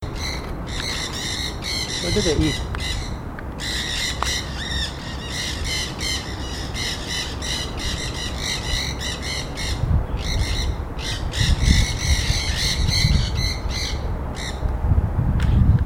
Periquito-de-cabeça-preta (Aratinga nenday)
Nome em Inglês: Nanday Parakeet
Fase da vida: Adulto
Localidade ou área protegida: Reserva Ecológica Costanera Sur (RECS)
Condição: Selvagem
Certeza: Observado, Gravado Vocal